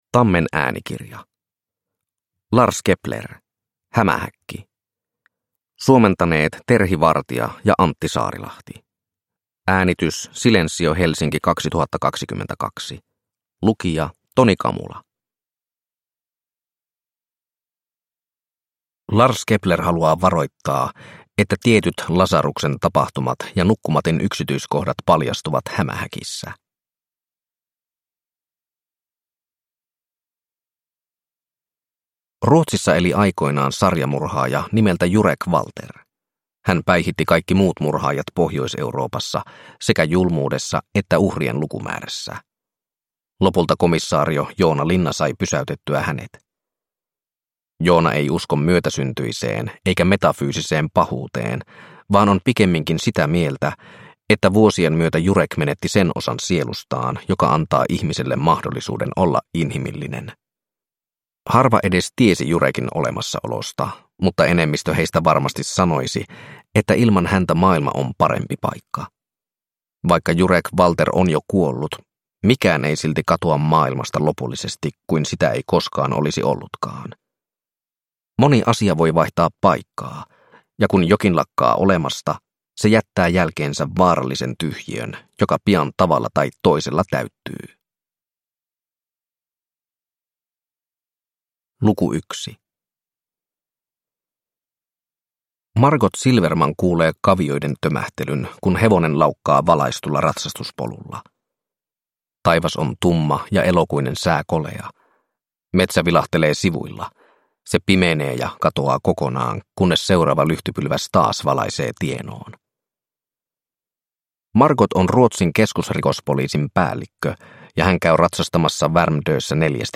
Hämähäkki – Ljudbok – Laddas ner